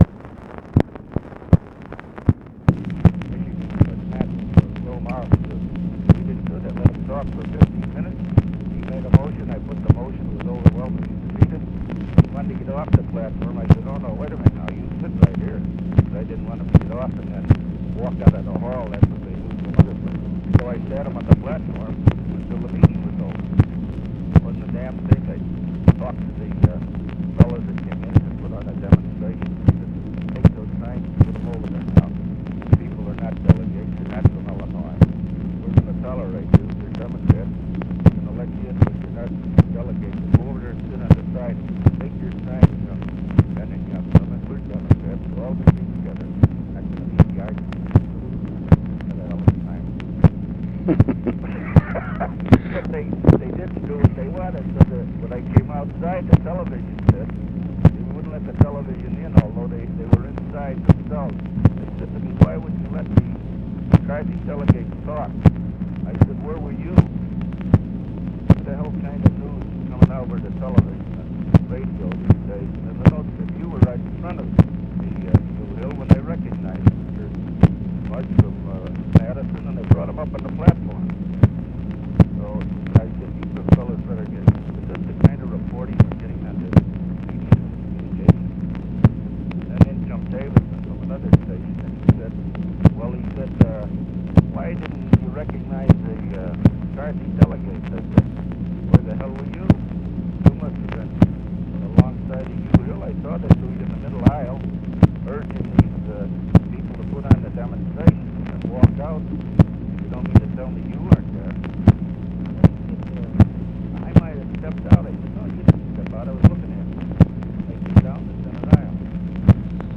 Conversation with RICHARD DALEY, July 13, 1968
Secret White House Tapes